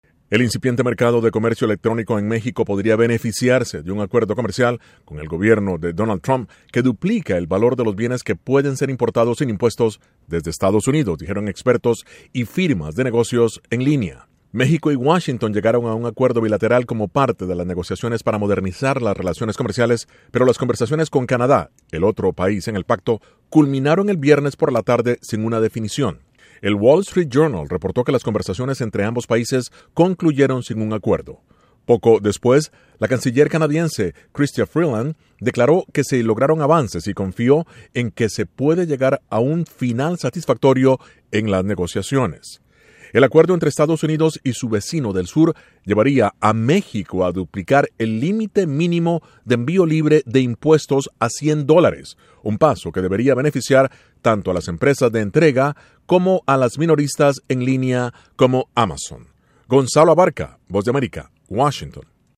Acuerdo comercial con EE.UU. impulsaría a empresas de comercio electrónico en México. Informa desde la Voz de América en Washington